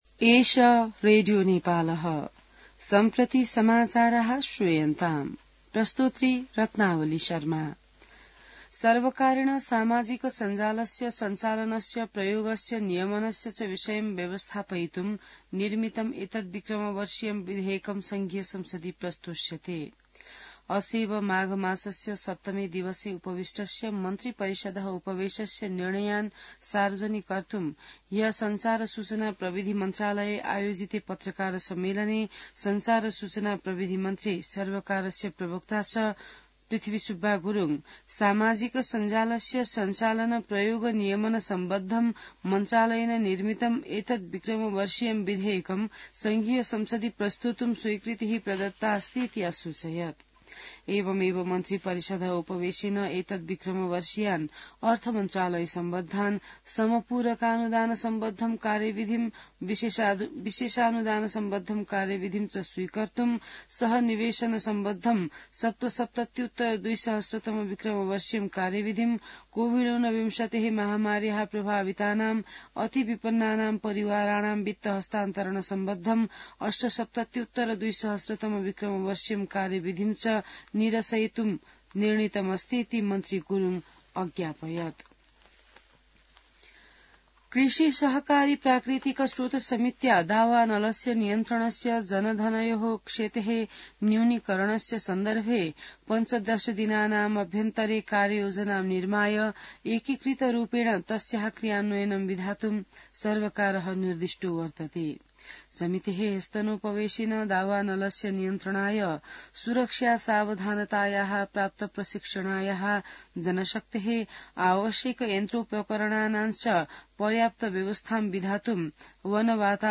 An online outlet of Nepal's national radio broadcaster
संस्कृत समाचार : ११ माघ , २०८१